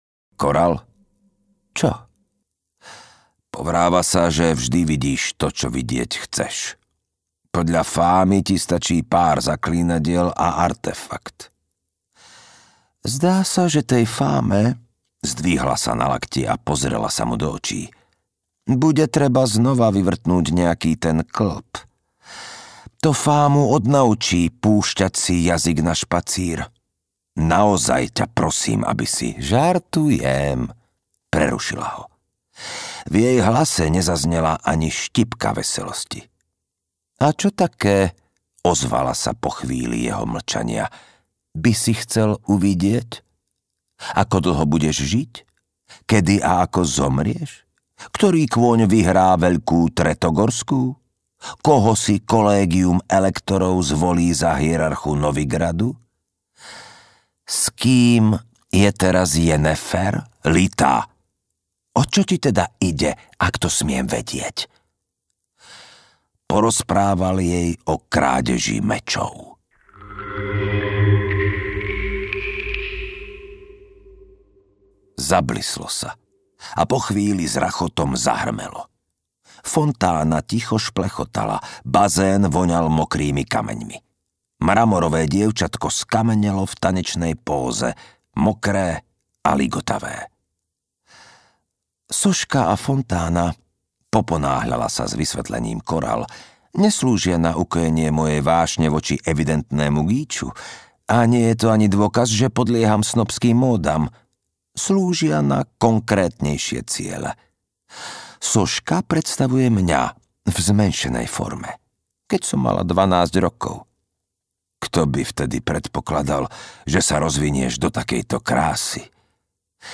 Audiokniha: Zaklínač VIII - Búrková sezóna
Audiokniha pre dospelých